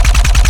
Added more sound effects.
GUNAuto_RPU1 C Loop_02_SFRMS_SCIWPNS.wav